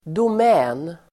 Ladda ner uttalet
Uttal: [dom'ä:n]